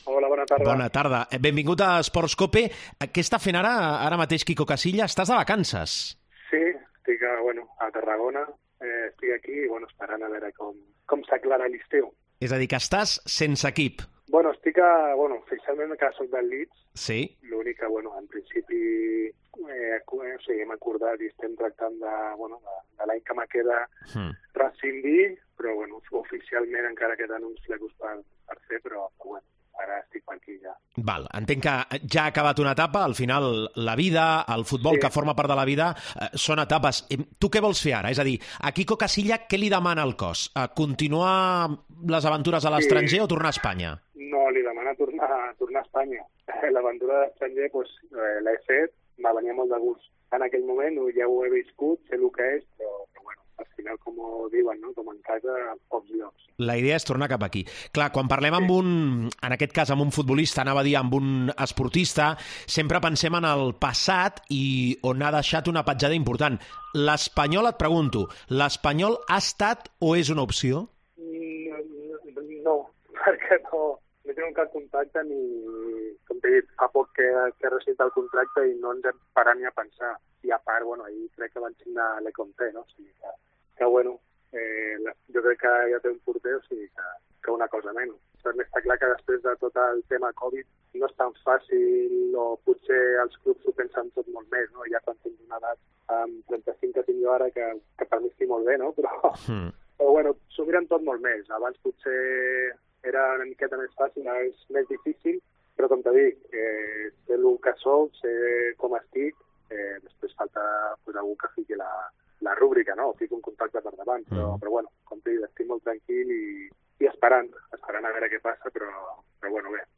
Entrevista en Esports COPE